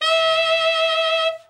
Index of /90_sSampleCDs/Giga Samples Collection/Sax/TENOR OVERBL
TENOR OB  22.wav